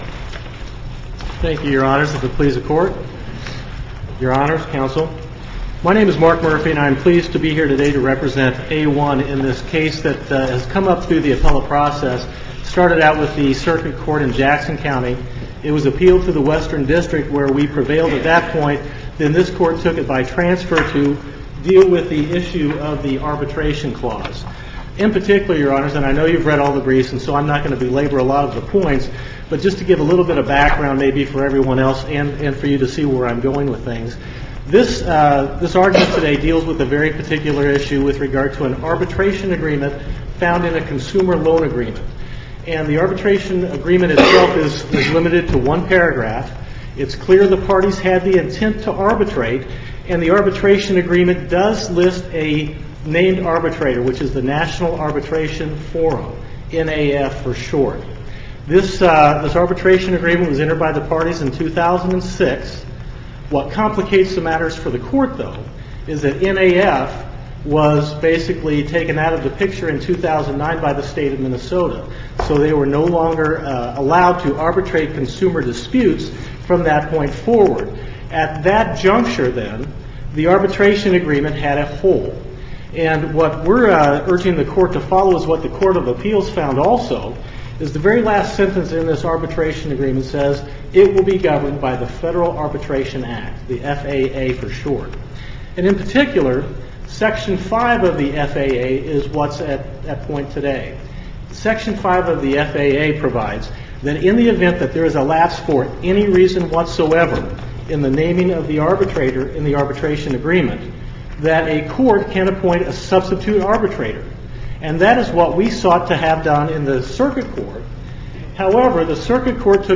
MP3 audio file of arguments before the Supreme Court of Missouri in SC96672